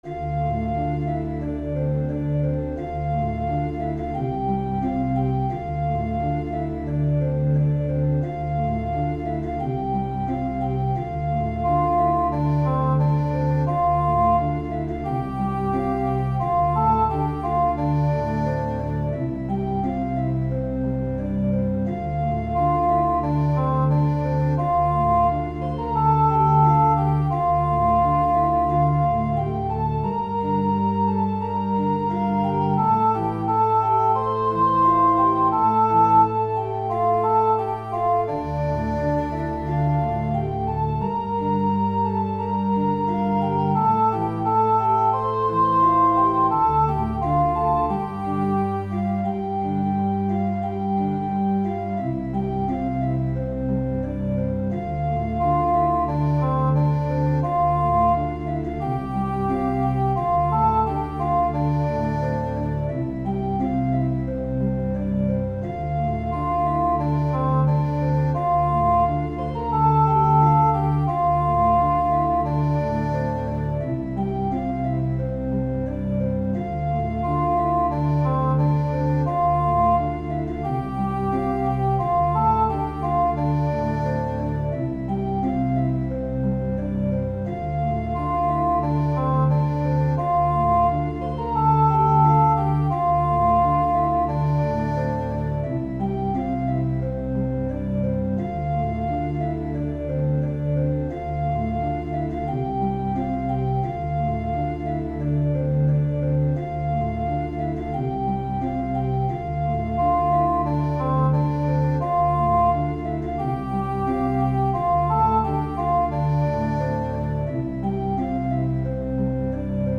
Choir Unison, Organ/Organ Accompaniment
Voicing/Instrumentation: Choir Unison , Organ/Organ Accompaniment